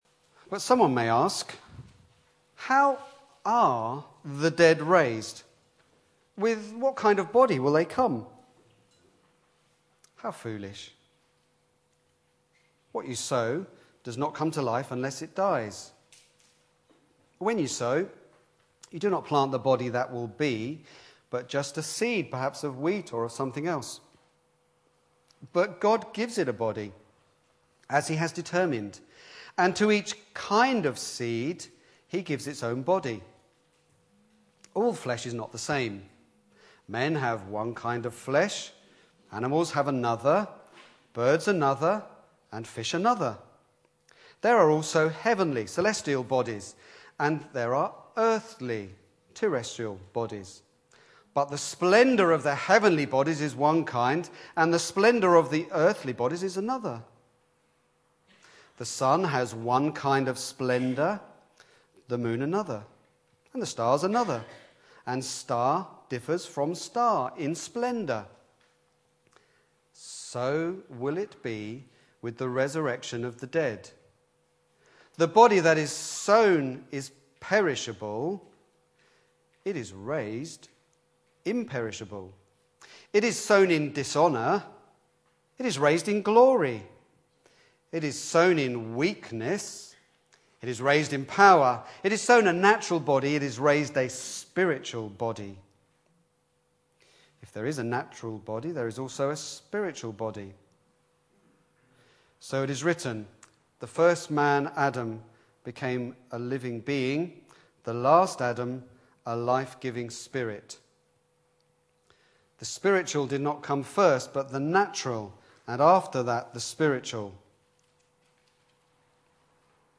Back to Sermons A New Body